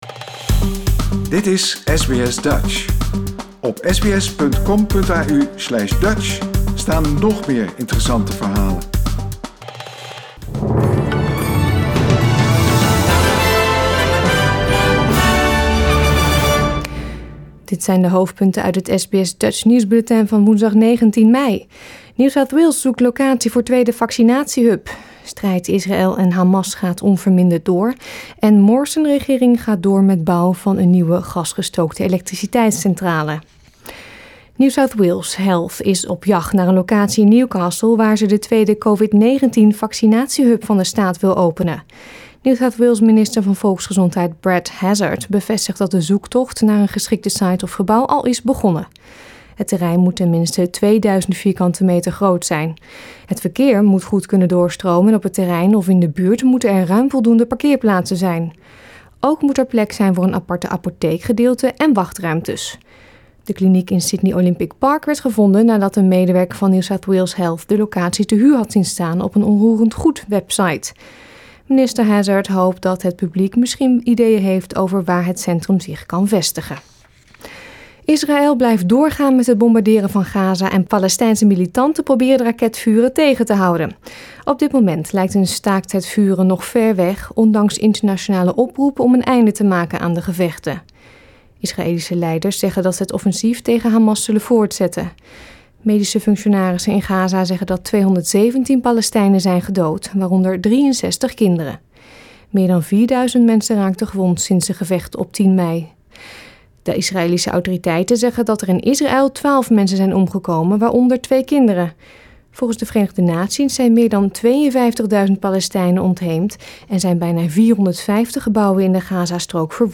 Nederlands/Australisch SBS Dutch nieuwsbulletin van woensdag 19 mei 2021